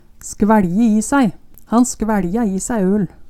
skvæLje i sæi - Numedalsmål (en-US)